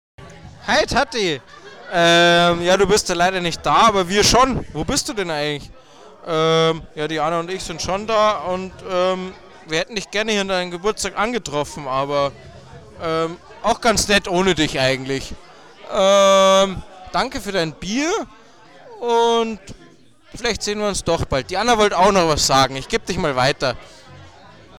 Wir verwenden hochwertige Soundtechnik und optimieren die Aufnahmen nachträglich.
Eure Begrüßung und die Nachrichten Eurer Gäste werden direkt am Telefon aufgenommen.
• Hochwertiges Mikrofon inklusive Lowcut-Filter